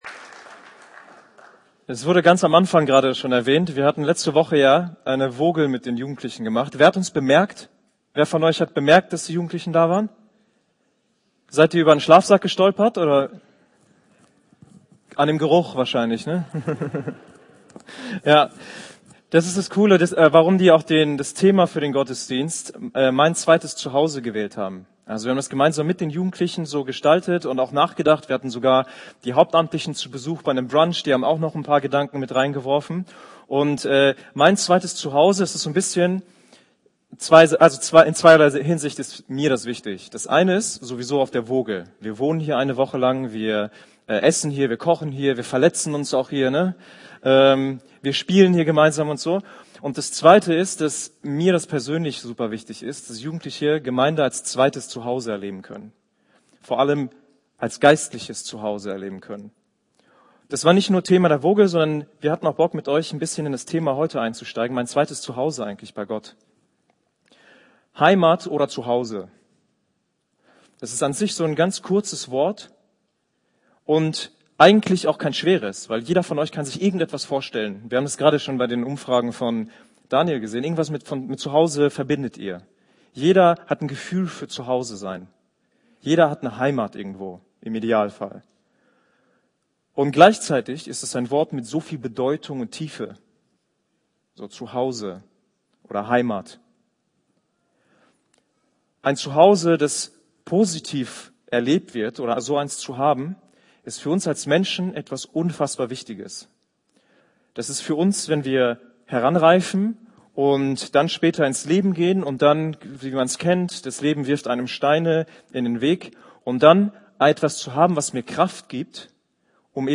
Jugendgottesdienst
Predigt